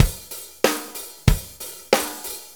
Shuffle Loop 28-04.wav